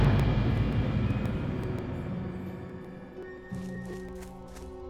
Buenas a todos, tengo un problema con el Client 3.3.5a que se escucha como un microcorte en el sonido probando multiples configuraciones ninguno lo soluciono.